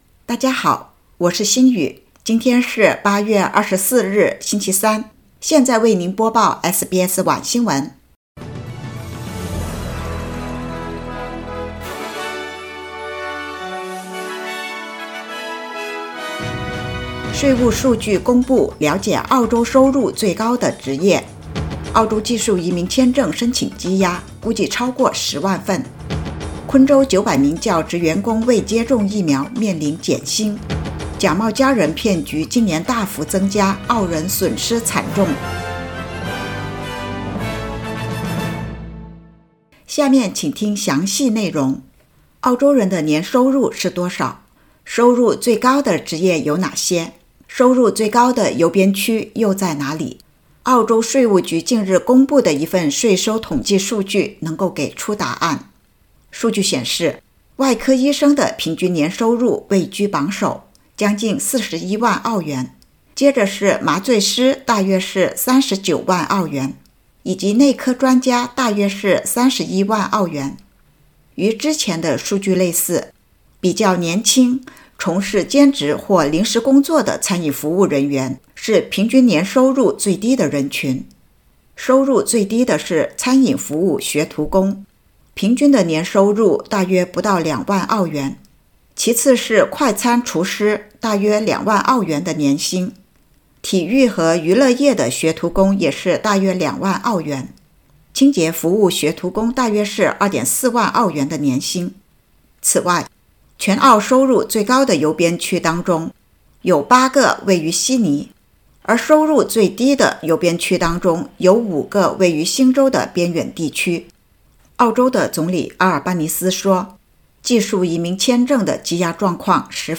SBS晚新闻（2022年8月24日）
SBS Mandarin evening news Source: Getty / Getty Images